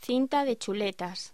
Locución: Cinta de chuletas
voz